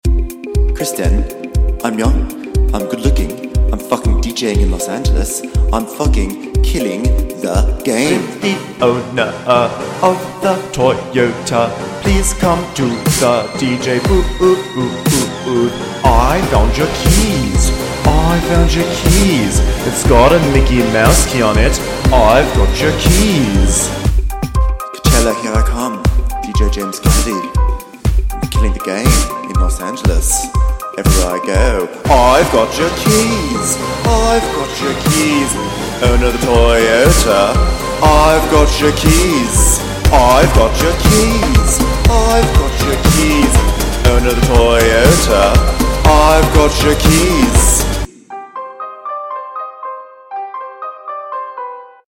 New club banger inspired by Vanderpump Rules's illest DJ, James Kennedy!